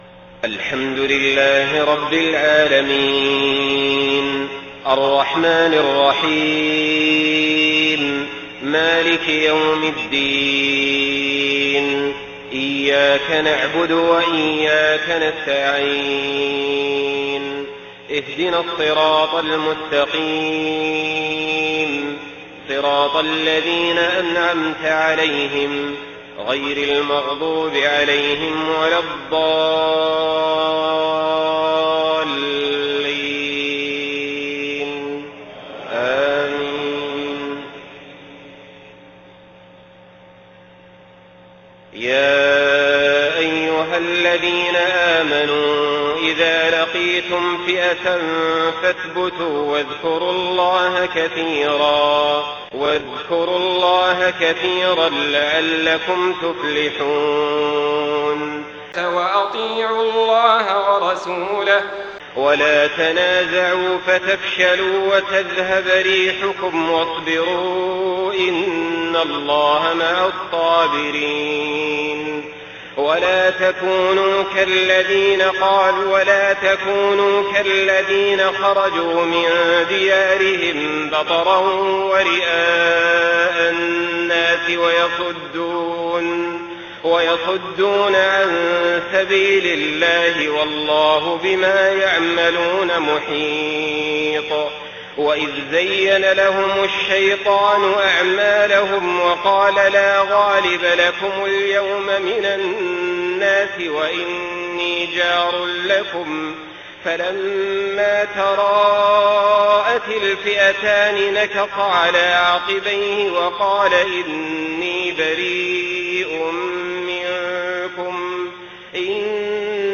صلاة العشاء 8 محرم 1430هـ من سورة الأنفال 45-60 > 1430 🕋 > الفروض - تلاوات الحرمين